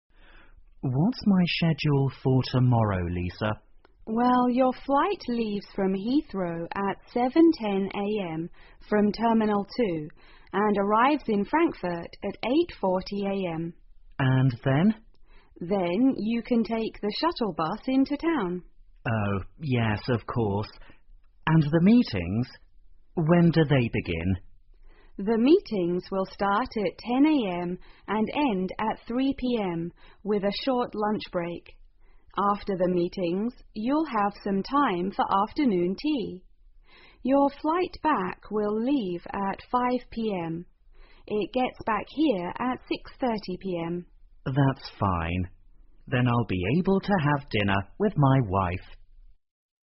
2015年高考(北京卷)英语听力真题 长对话(1) 听力文件下载—在线英语听力室